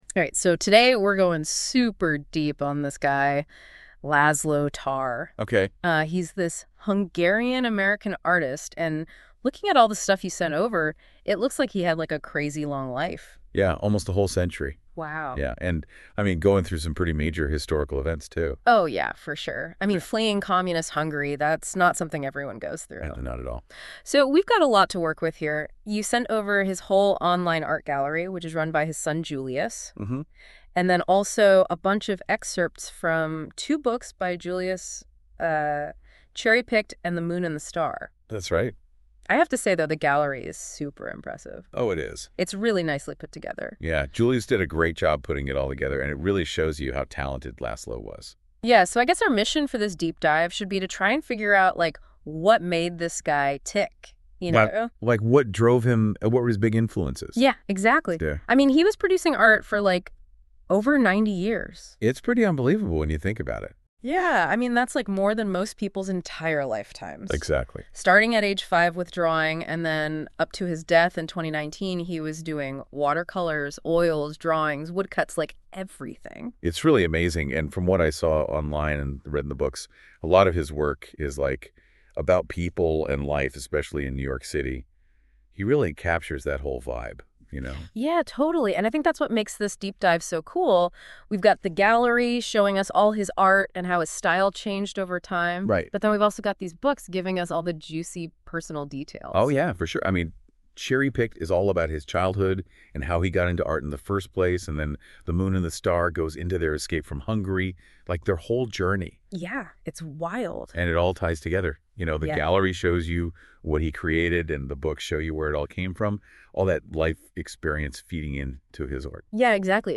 Sit back and enjoy an interesting discussion about the man and his art.